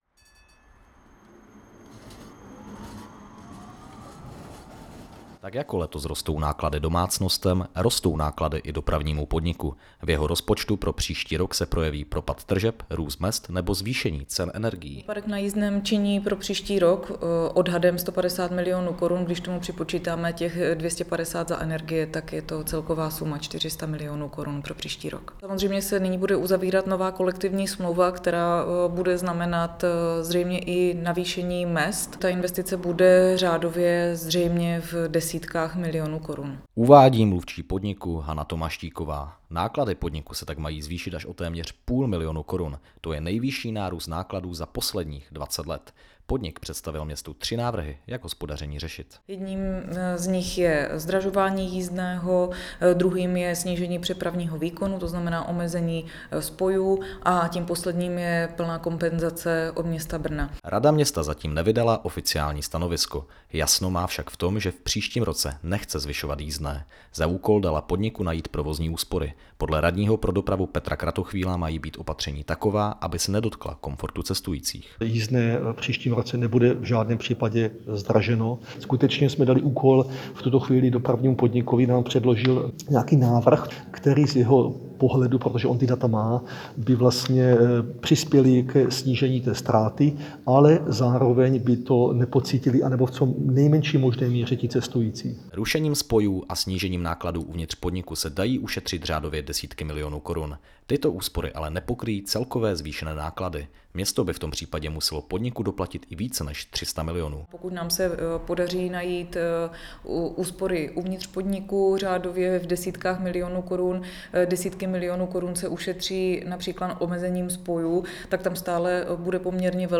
Více v reportáži.